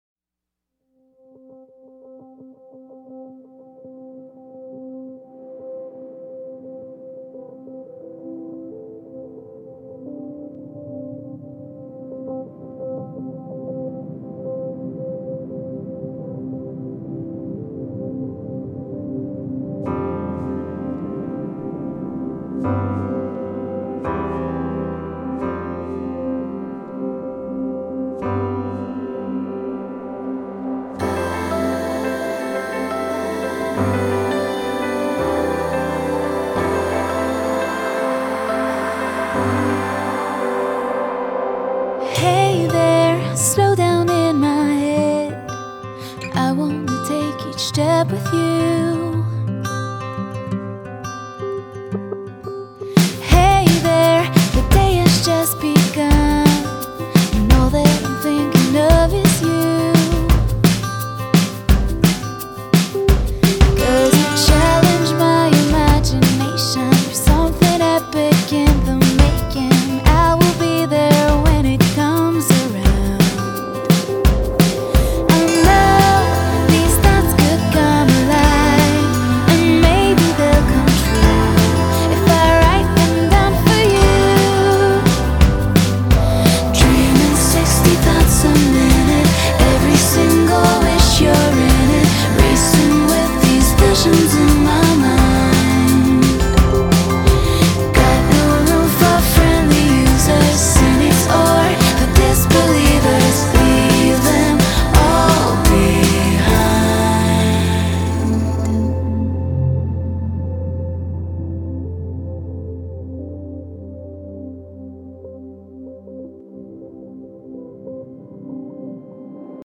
Genre.........................: Pop